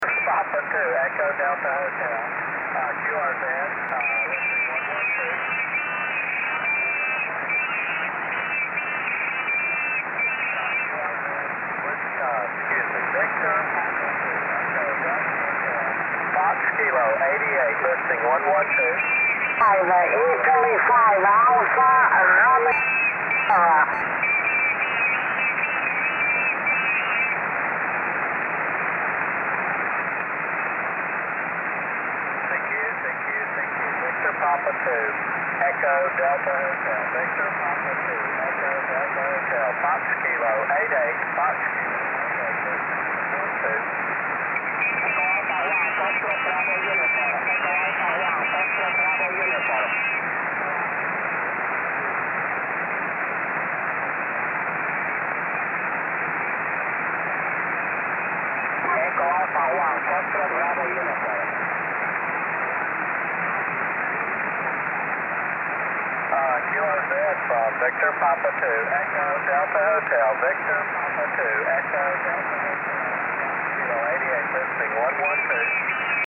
Here there are few soundclips of famous Dxpeditions and not of last years on HF and 6 meter,
i hope to add more in the future, now i try to record all my qso ! Hi !